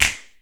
• Hand Clap A# Key 36.wav
Royality free clap - kick tuned to the A# note. Loudest frequency: 3599Hz
hand-clap-a-sharp-key-36-UdB.wav